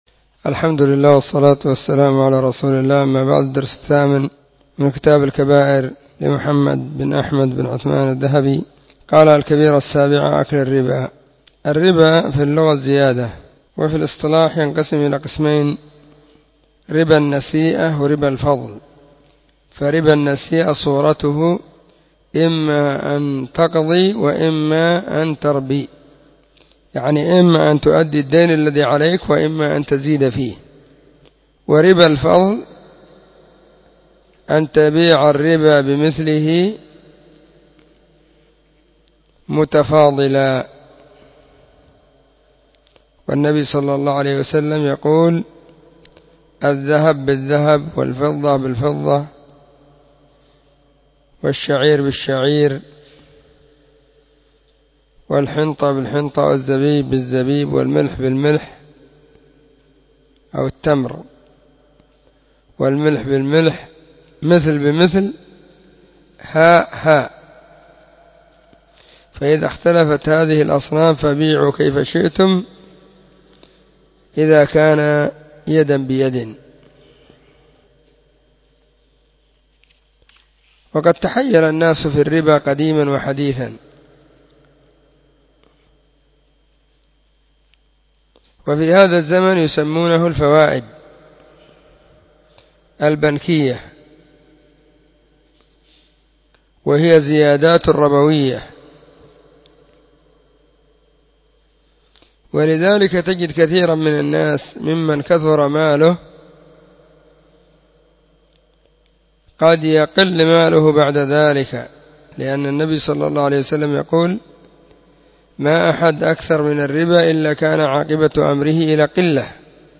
📢 مسجد الصحابة – بالغيضة – المهرة، اليمن حرسها الله.…
🕐 [بين مغرب وعشاء – الدرس الثاني]